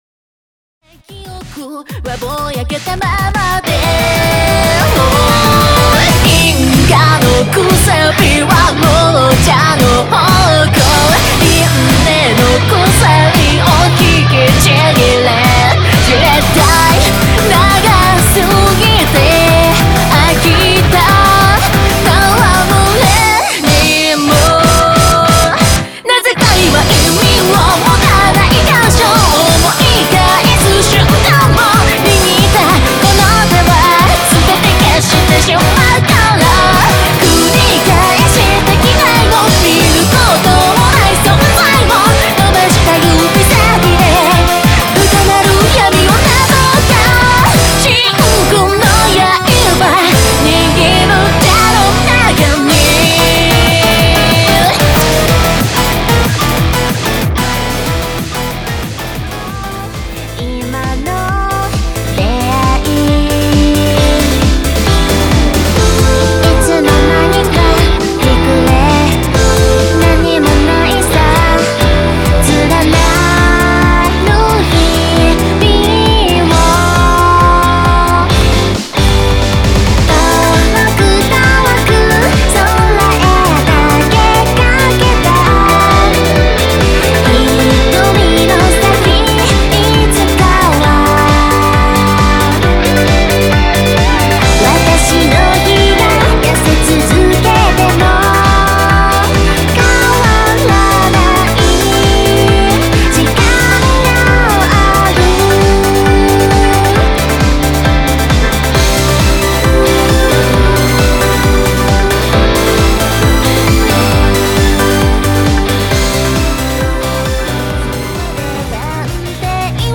（全6曲フルヴォーカル＋カラオケ収録）
■Vocal
■Guitar